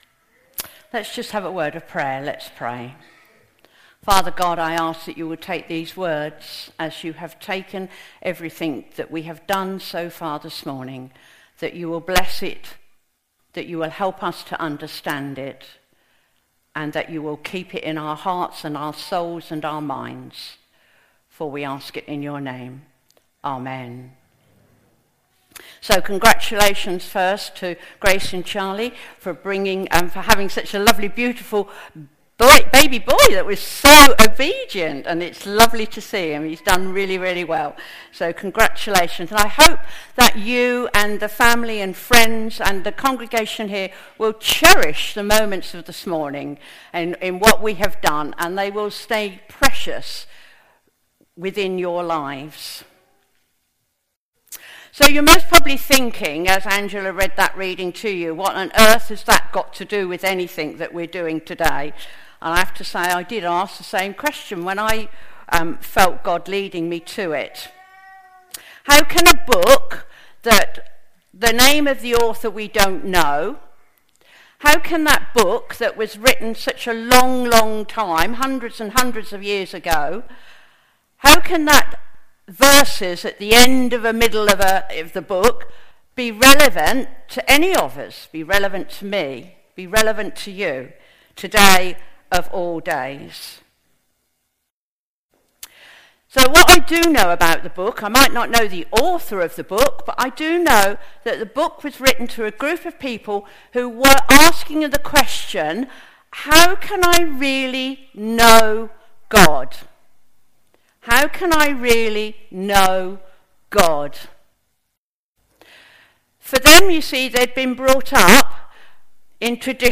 An audio version of the sermon is also available.
Service Type: Sunday Morning
05-12-sermon.mp3